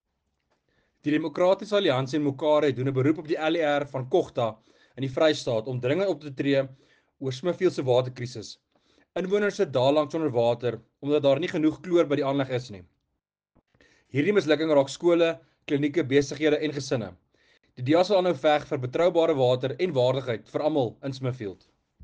Afrikaans soundbite by Werner Pretorius MPL, and